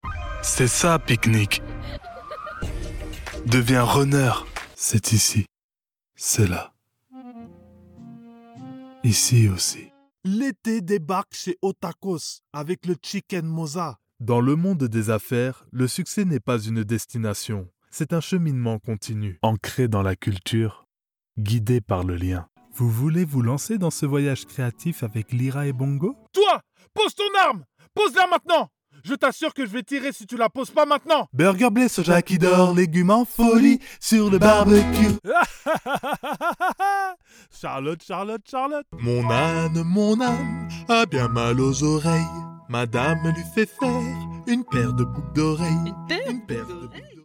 Both male and female native French speakers offer professional recordings from broadcast quality studios saving you money and time.
French European Voice Over Actors
French (Parisienne)
Yng Adult (18-29) | Adult (30-50)